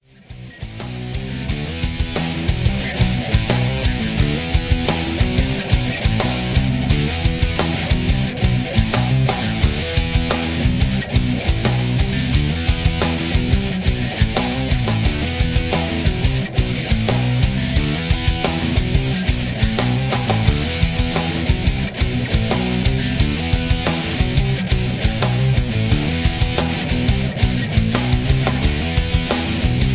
(Key-Em) Karaoke MP3 Backing Tracks